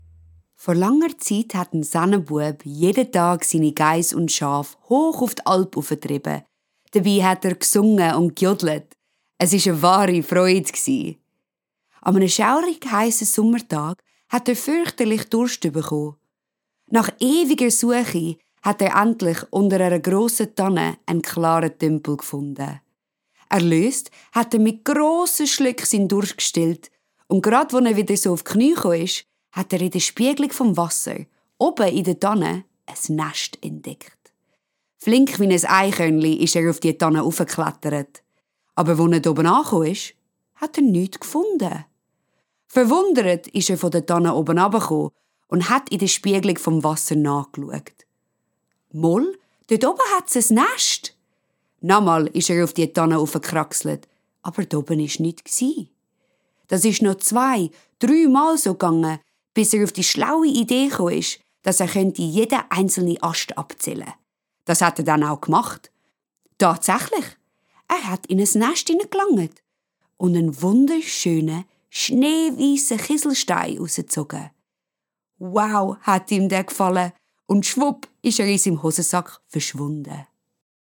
European, German, Swiss-German, Female, Home Studio, 20s-30s